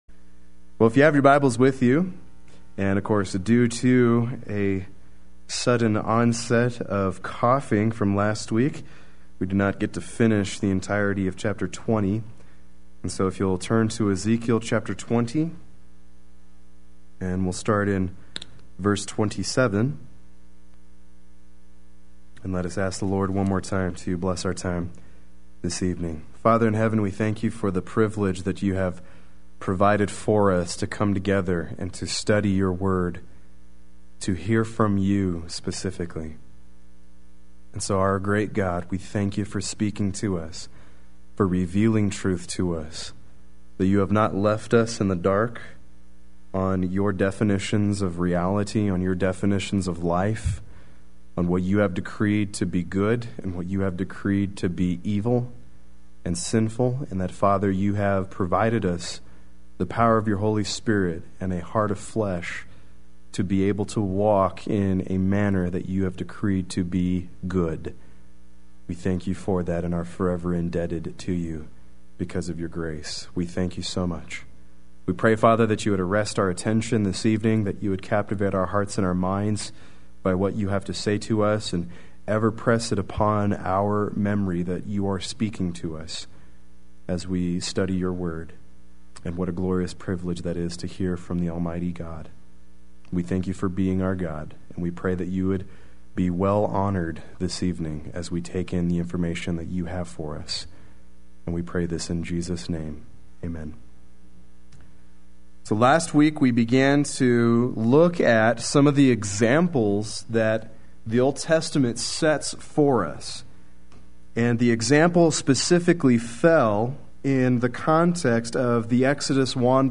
Play Sermon Get HCF Teaching Automatically.
Chapter 20 Wednesday Worship